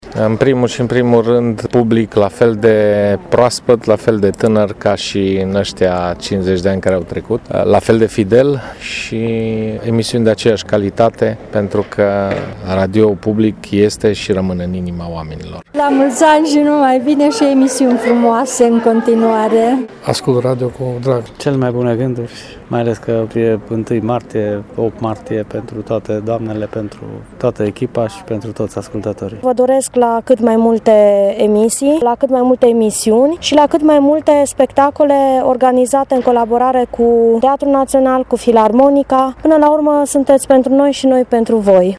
Nici ascultătorii noștri nu ne-au uitat, și drept răsplată, ne-au transmis cele mai frumoase urări .